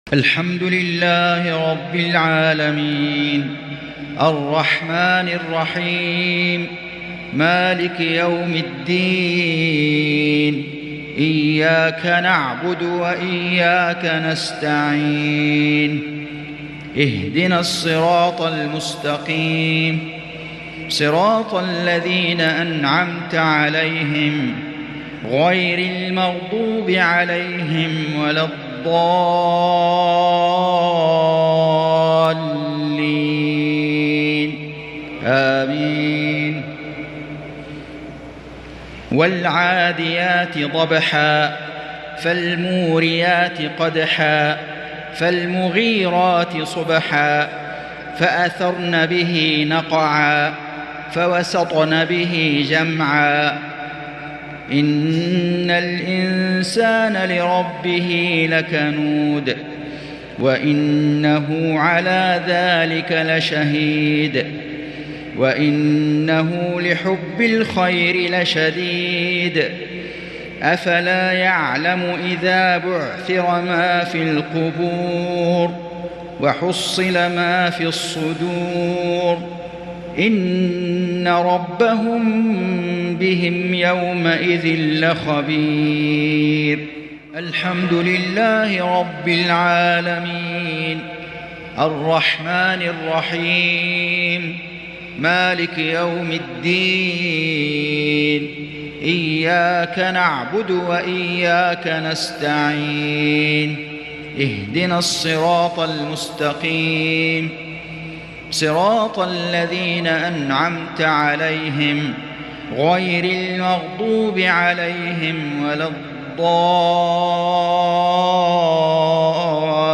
صلاة المغرب ٤محرم ١٤٤٤هـ سورتي العاديات والهمزة | Maghrib prayer from Surah al-`Adiyat & al-Humazah 2-8-2022 > 1444 🕋 > الفروض - تلاوات الحرمين